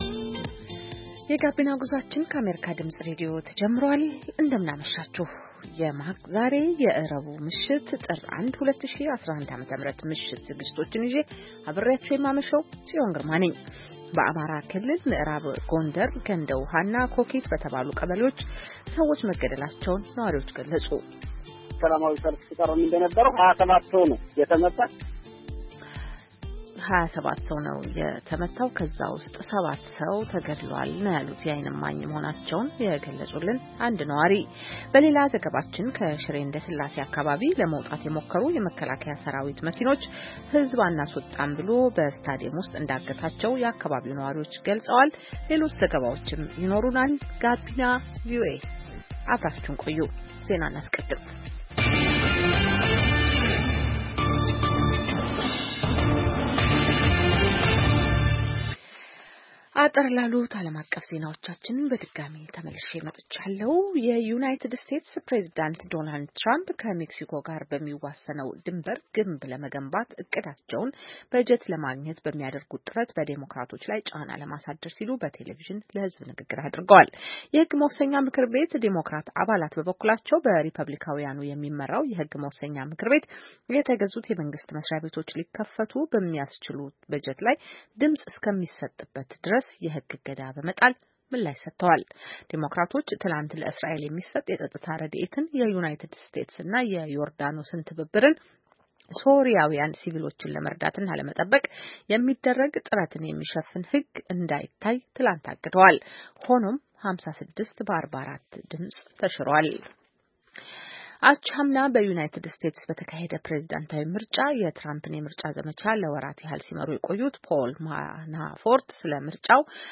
Gabina VOA is designed to be an infotainment youth radio show broadcasting to Ethiopia and Eritrea in the Amharic language. The show brings varied perspectives on issues concerning young people in the Horn of Africa region.